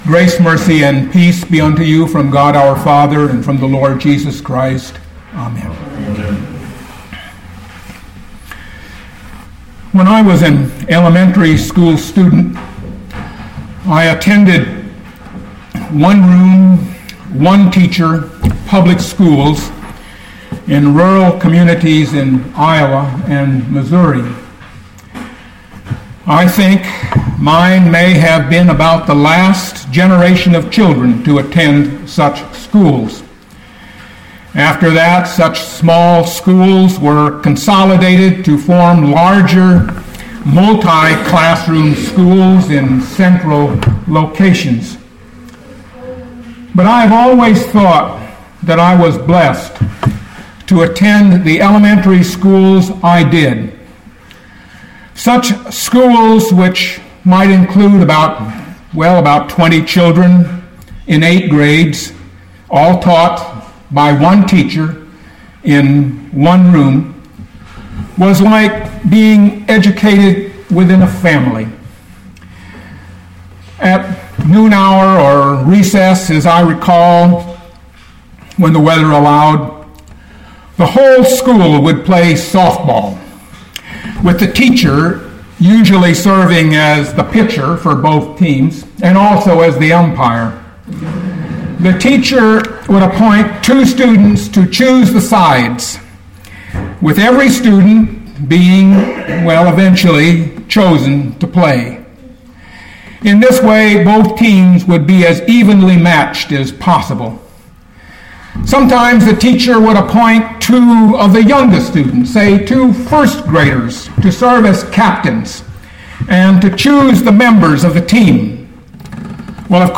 2014 Matthew 3:13-17 Listen to the sermon with the player below, or, download the audio.